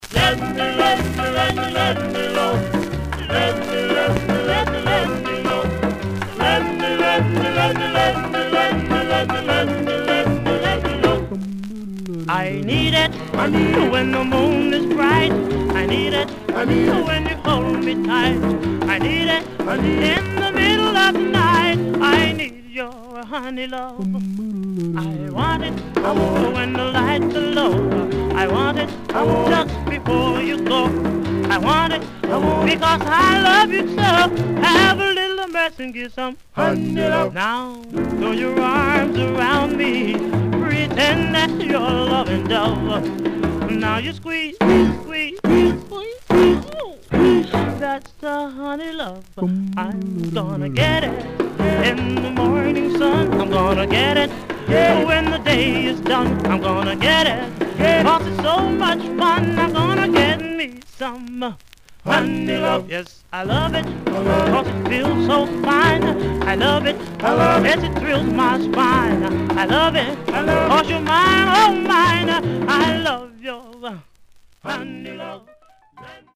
Mono
Male Black Group